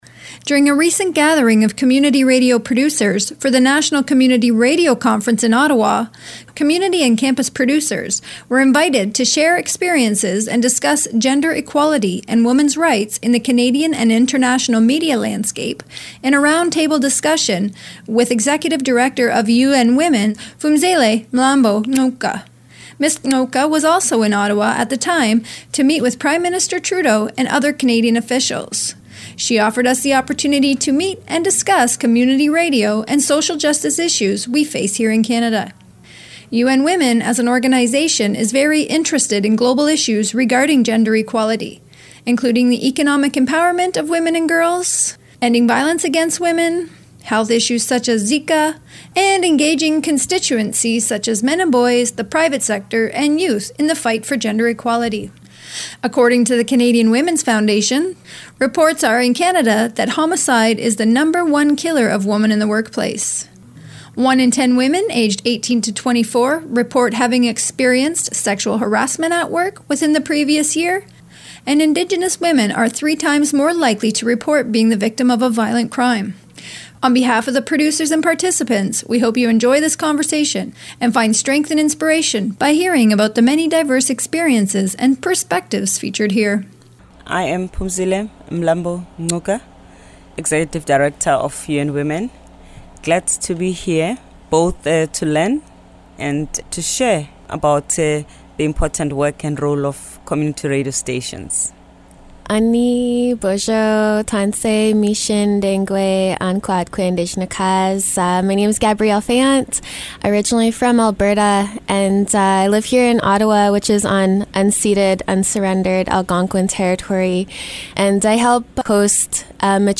UN Women NCRC Round Table Discussion (June 2016)
File Information Listen (h:mm:ss) 0:28:56 NCRA/UN Women Round Table Discussion on Issues Facing Women in the Media Sector Download (5) NCRA_UN_Womens_Panel_discussion_June_2016.mp3 41,681k 192kbps Stereo Listen All
NCRA_UN_Womens_Panel_discussion_June_2016.mp3